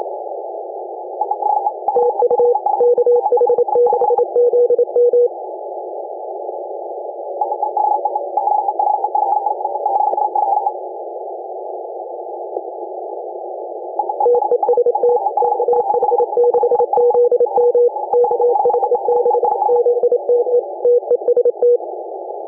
But not so this time, loud signals and no “polar flutter” as is often the case, just clear strong signals like these two: